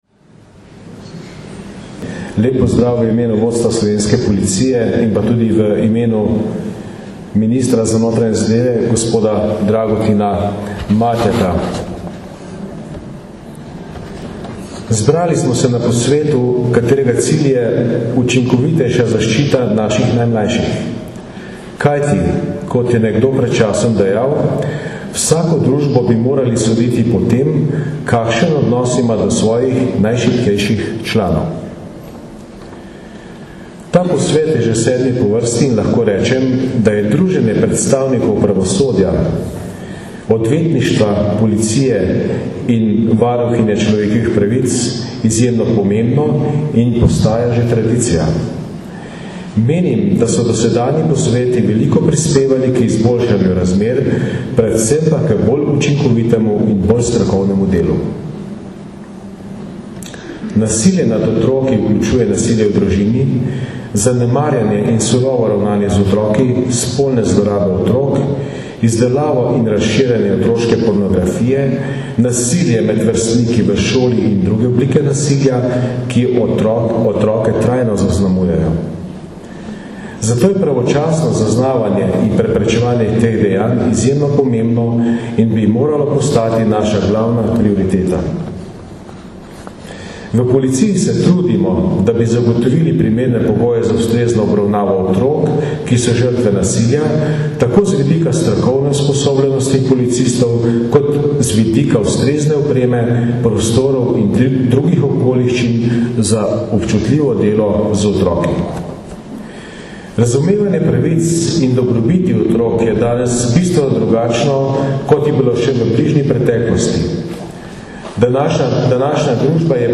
V Rogaški Slatini poteka danes in jutri, 3. in 4. 4. 2008, posvet z naslovom Nasilje, kaj še lahko storimo, ki ga vsako leto organizirata Uprava kriminalistične policije na Generalni policijski upravi in Društvo državnih tožilcev Slovenije.
Jože Romšek, generalni direktor policije
Zvočni posnetek izjave generalnega direktorja policije Jožeta Romška (mp3)